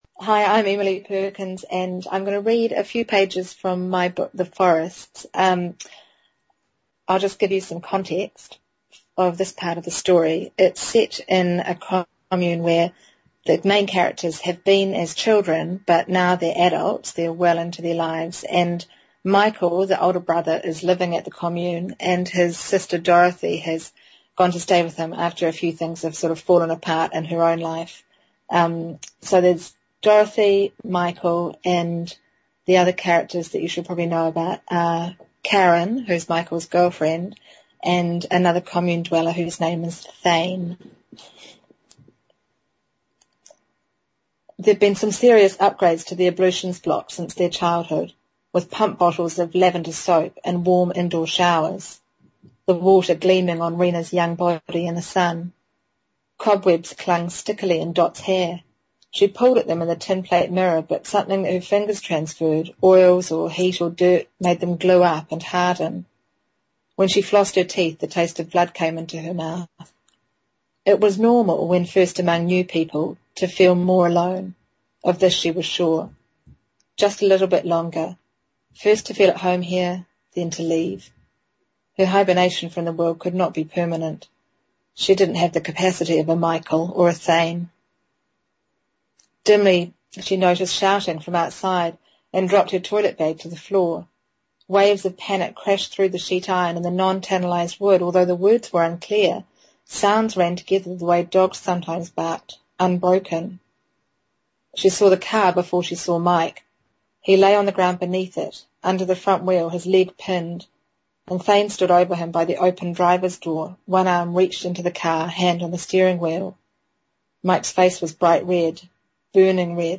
Emily Perkins reading
Emily Perkins reads from her novel The Forrests for the New Zealand Listener Book Club.